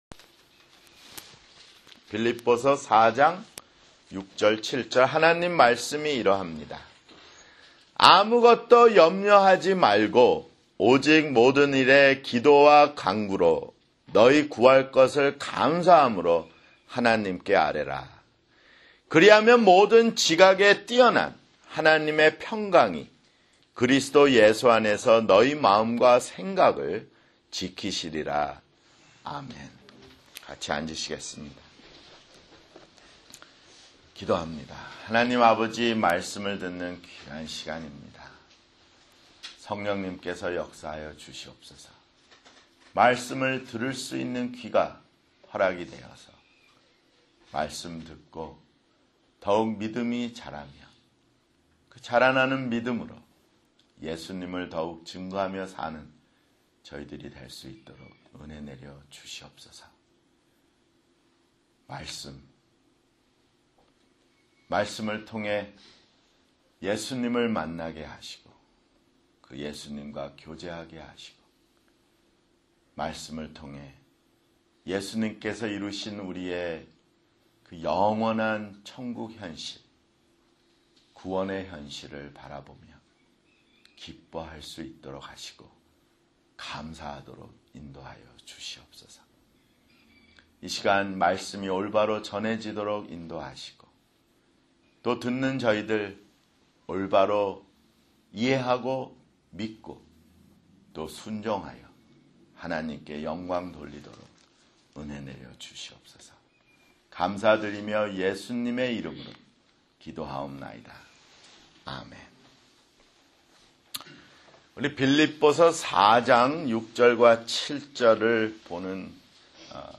[주일설교] 빌립보서 (55)